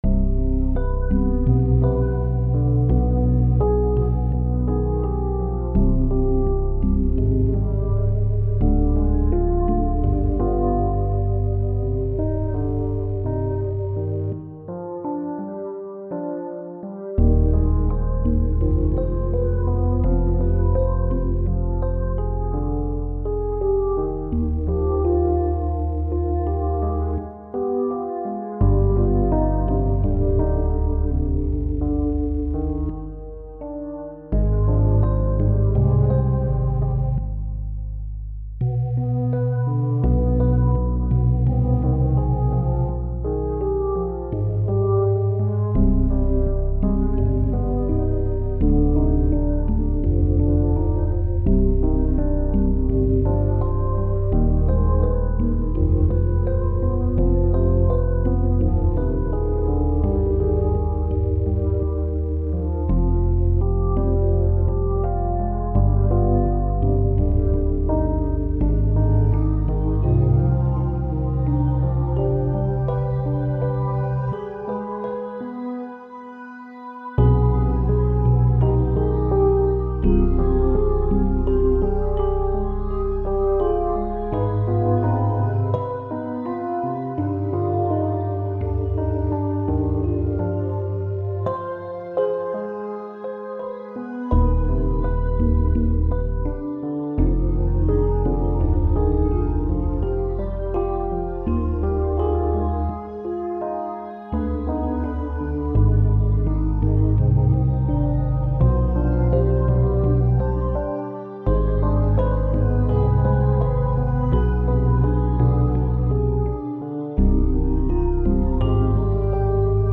On repart sur une série "Fractale Piano".
Morse (14 - 150 ) Fa (F) Minor Harmon. 84
Plugins : MDA Piano, Organized trio, synth1